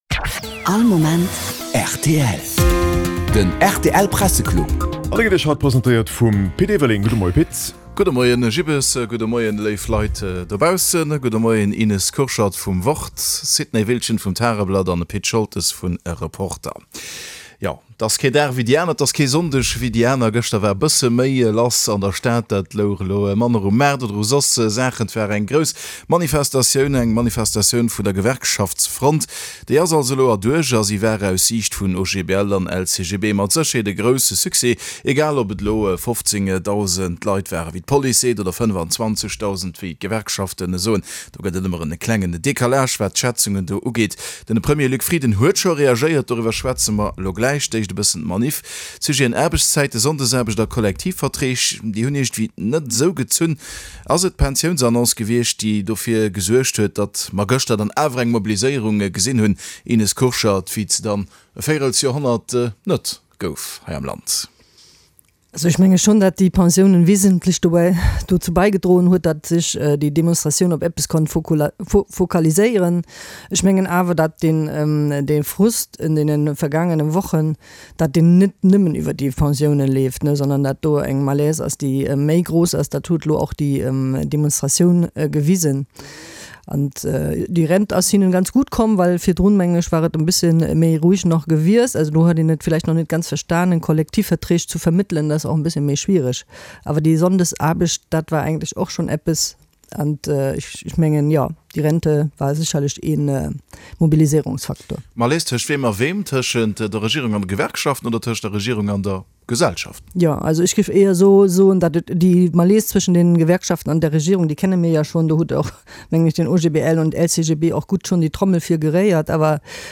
Vertrieder vun der geschriwwener Press iwwert d'Aktualitéit vun der leschter Woch